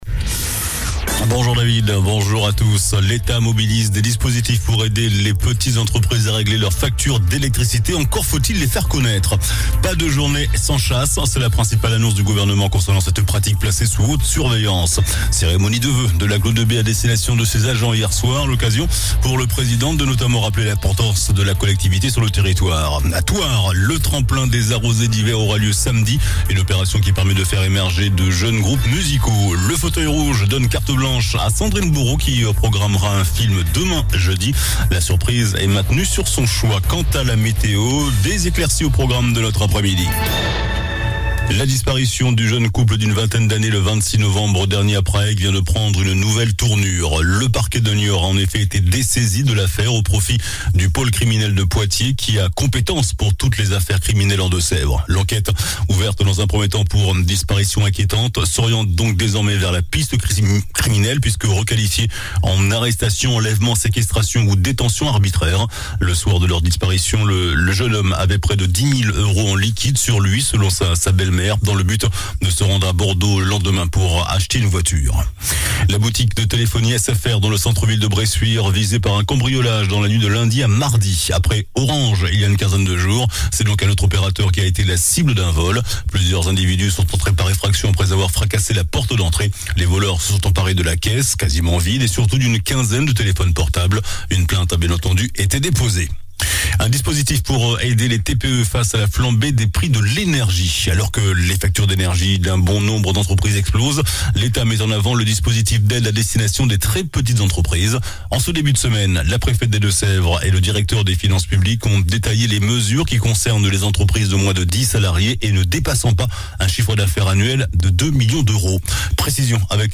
JOURNAL DU MERCREDI 11 JANVIER ( MIDI )